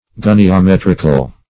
Search Result for " goniometrical" : The Collaborative International Dictionary of English v.0.48: Goniometric \Go`ni*o*met"ric\, Goniometrical \Go`ni*o*met"ric*al\, a. Pertaining to, or determined by means of, a goniometer; trigonometric.